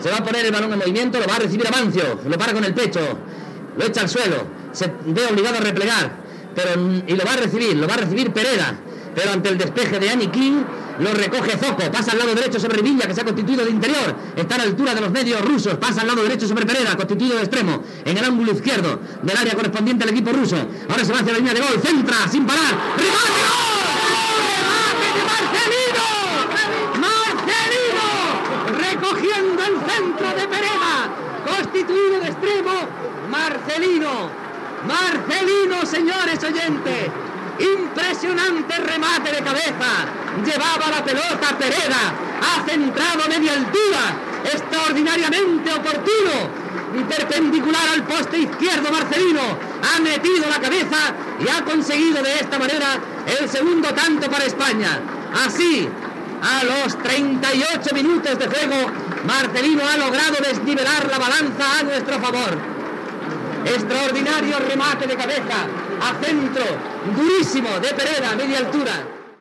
Narració del gol de Marcelino que suposava el 2-1 i el triomf de la selecció espanyola.
Esportiu